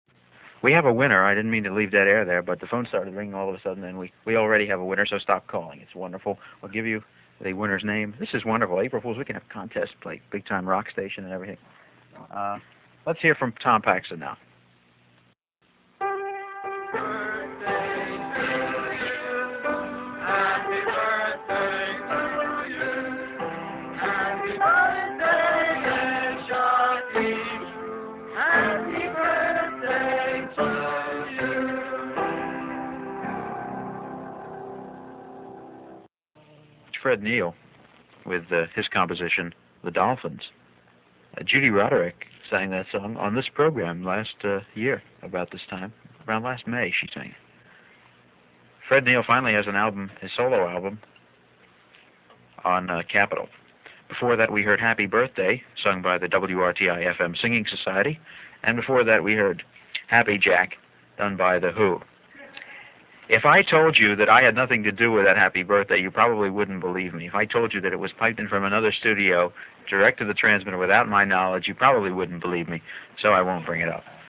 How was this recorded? Only the surprise middle song is heard.)